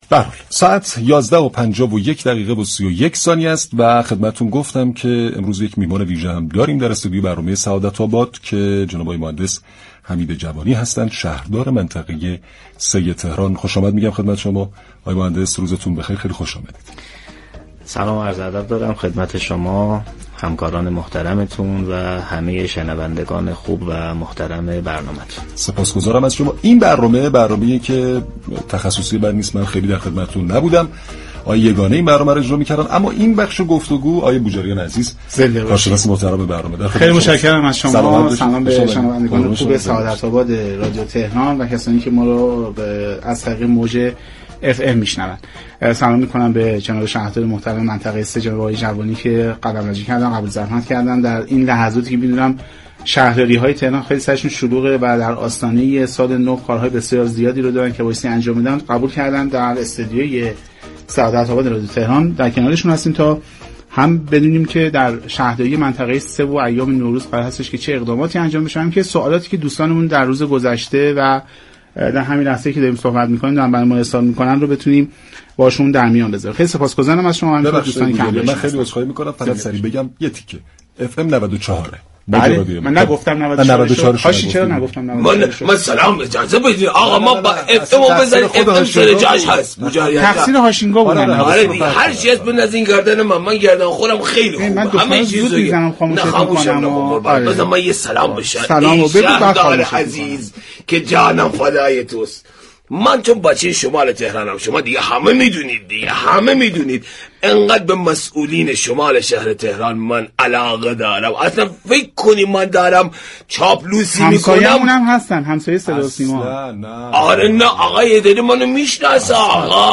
به گزارش پایگاه اطلاع رسانی رادیو تهران؛ حمید جوانی شهردار منطقه 3 شهر تهران كه برخی نقاط گردشگری مدرن تهران در آن واقع شده با حضور در استودیو پخش زنده رادیو تهران و در گفت و گو با برنامه «سعادت آباد» در خصوص برنامه‌های شهرداری در نوروز 1402 اظهار داشت: پیش بینی ما این است كه نقاط گردشگری شهر تهران نسبت به سالهای گذشته به دلایل مختلف از جمله تقارن ماه مبارك رمضان با عید نوروز پر تراكم‌تر خواهد بود و سفرهای نوروزی شهروندان تهرانی به شهرهای دیگر كاهش خواهد یافت.